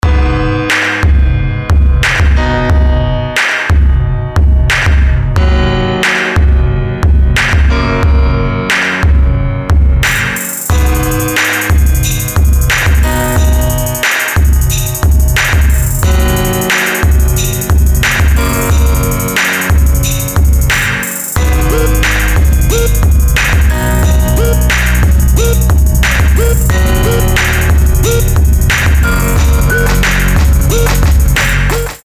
• Качество: 320, Stereo
Electronic
без слов
приятные
спокойный будильник